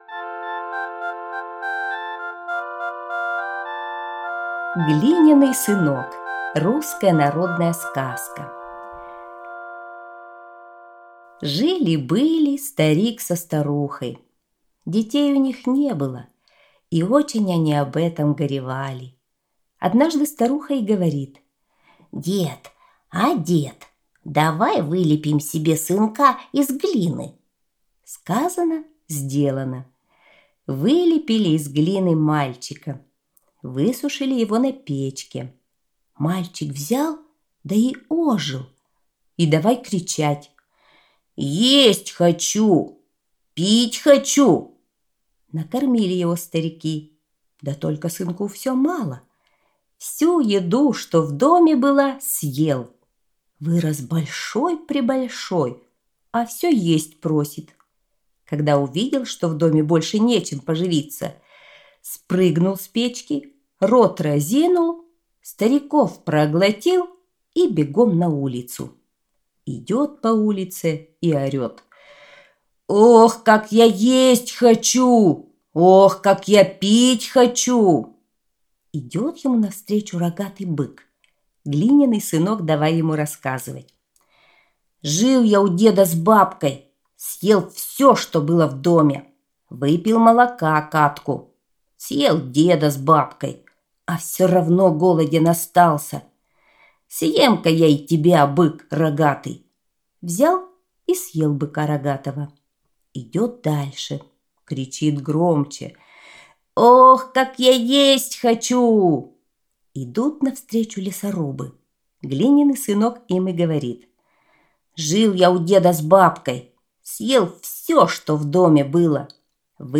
Глиняный сынок – русская народная аудиосказка